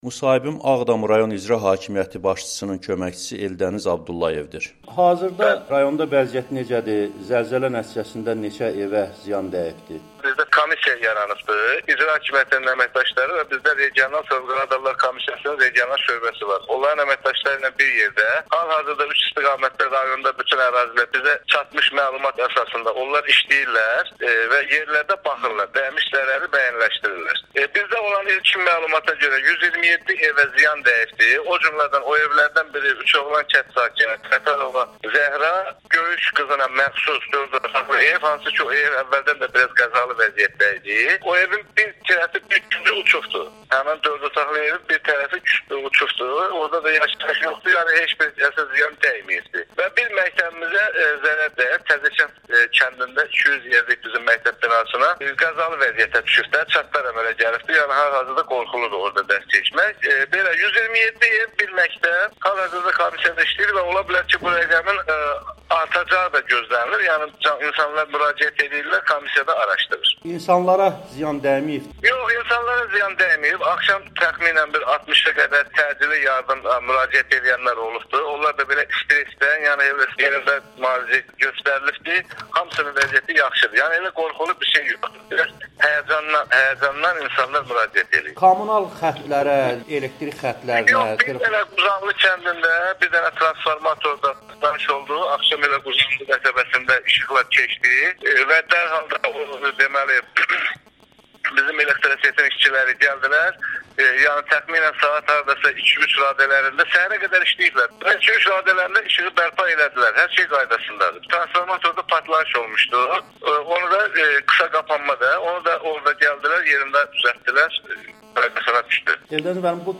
Zəlzələ nəticəsində çox sayda evə ziyan dəyib (Audo-müsahibələr)
Dünən Ağdam rayonu ərazisində (Bakıdan 351 kilometr cənubi-qərb) baş verəm zəlzələ nəticəsində Ağdam, Bərdə və Tərtər rayonunda tikililərə ziyan dəyib. Yerli icra hakimiyyətlərinun nümayəndələri Amerikanın Səsinin müxbiri ilə telefon söhbətlərində insanları xəsarət almadığını bildirib.